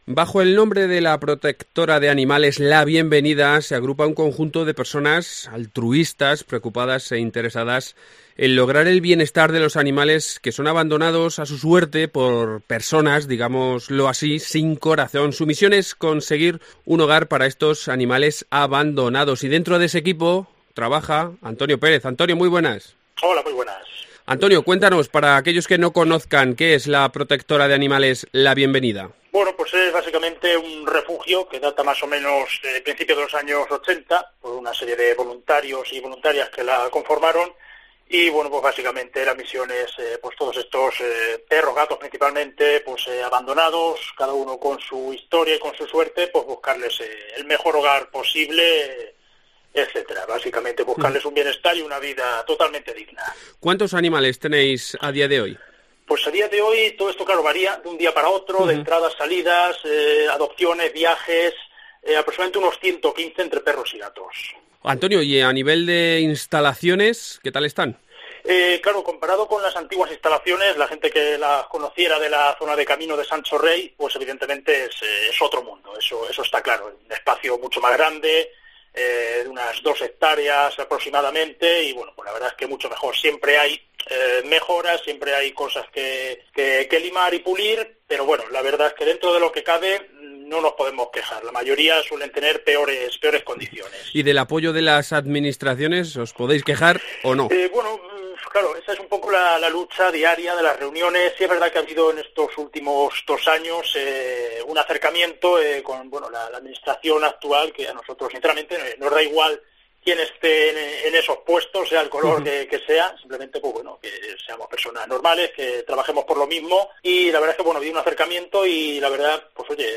COPE CIUDAD REAL